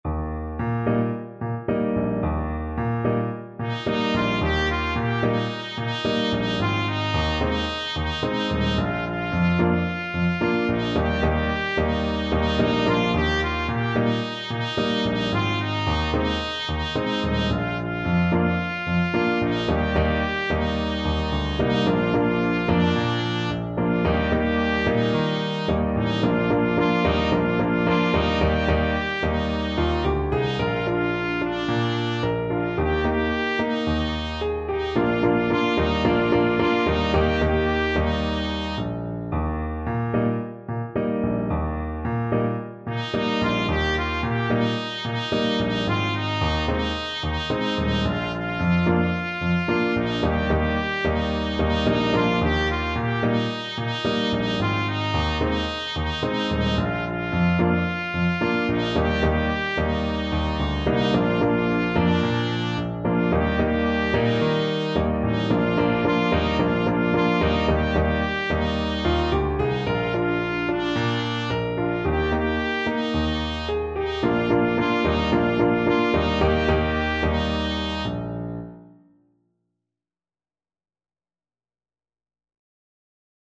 Trumpet
Eb major (Sounding Pitch) F major (Trumpet in Bb) (View more Eb major Music for Trumpet )
Allegro moderato =c.110 (View more music marked Allegro)
4/4 (View more 4/4 Music)
Easy Level: Recommended for Beginners with some playing experience
Traditional (View more Traditional Trumpet Music)
world (View more world Trumpet Music)